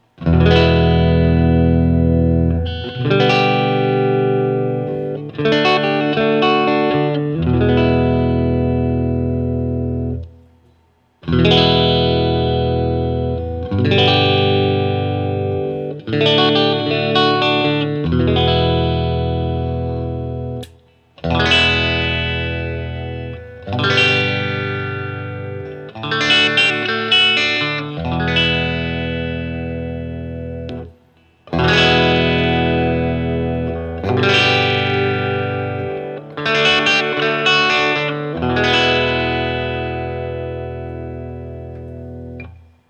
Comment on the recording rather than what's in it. I put the guitar through my usual rig which is the Axe-FX Ultra into the QSC K12 speaker using the Tiny Tweed, JCM 800, and Backline settings. Each recording goes though all of the pickup selections in the order: neck, both (in phase), both (out of phase), bridge.